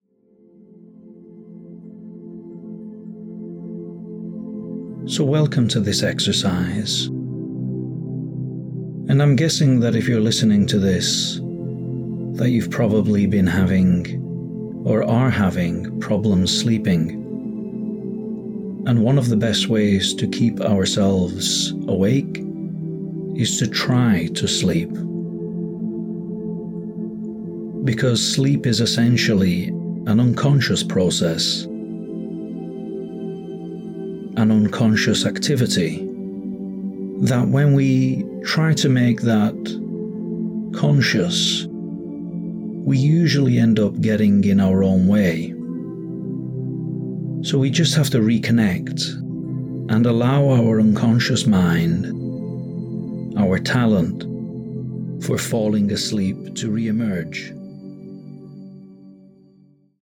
We need to allow our body to do what it already knows how to do, and one way we can do that is by managing our attention. So, as you listen to this recording, just let the words and sounds wash over you.